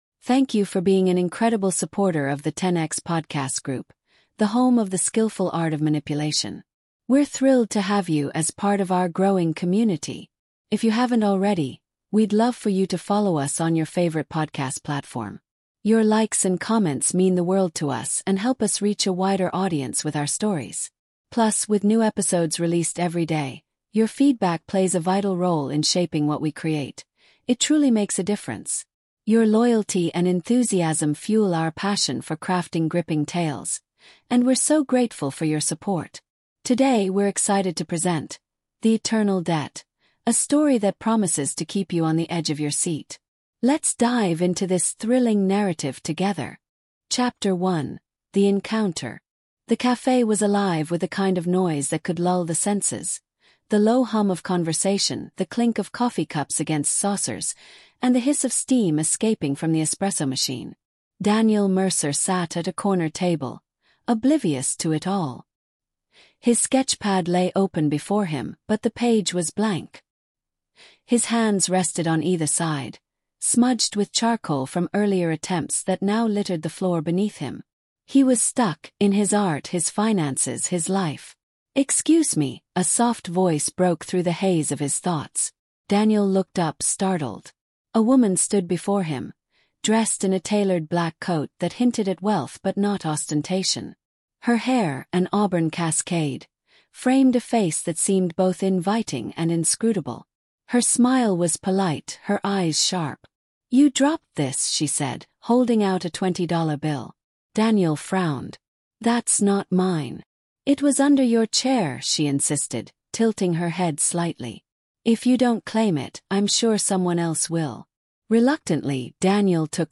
"The Eternal Debt" is a gripping storytelling podcast that weaves a masterful tale of manipulation, suspense, and psychological intrigue. Follow Daniel Mercer, a struggling artist, as he falls under the spell of the enigmatic Victoria Delacroix, a woman who convinces him he owes her an unpayable debt.